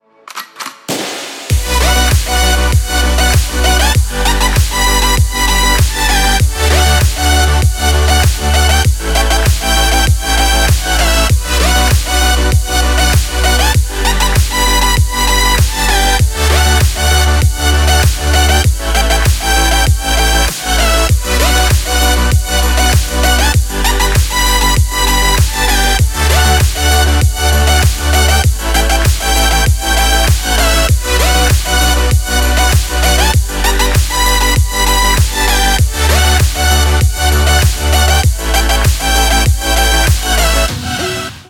• Качество: 128, Stereo
громкие
без слов
энергичные
Стиль: house